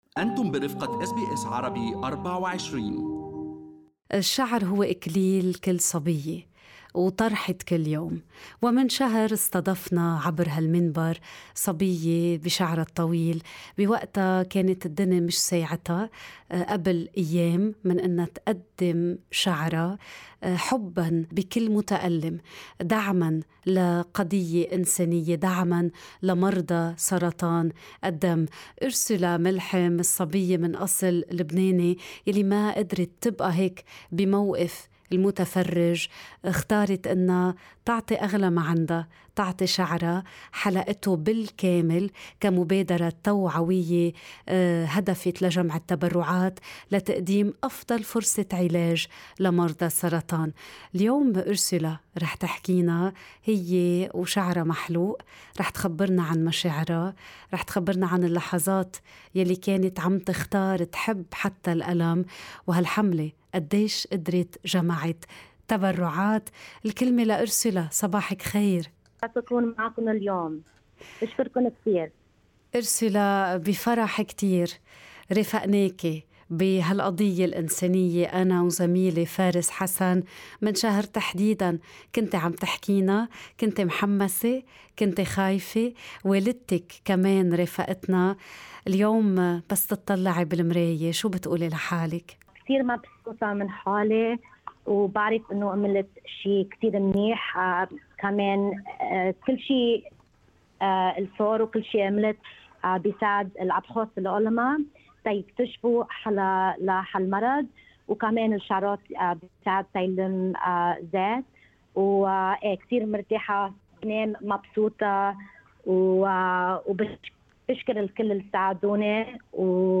المزيد في هذه المقابلة في الملف الصوتي المرفق أعلاه.